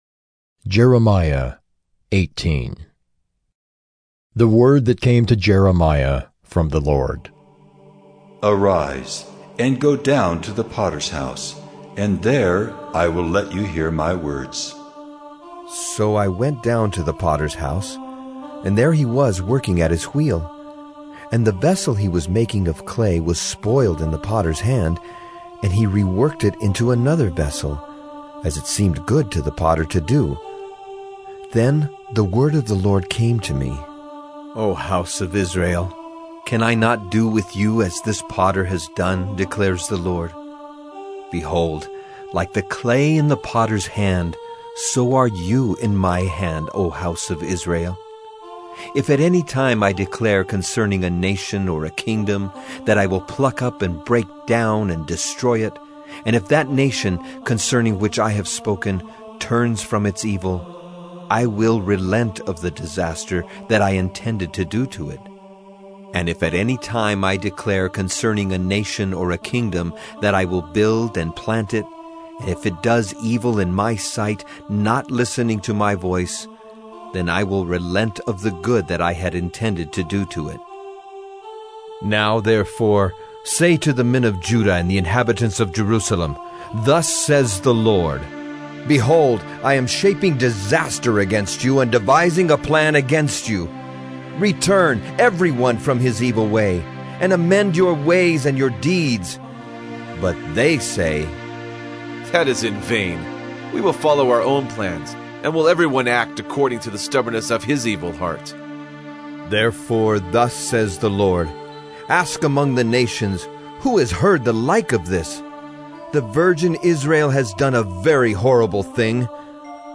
“Listening to God” Bible Reading & Devotion: Dec 26, 2025 – Jeremiah 018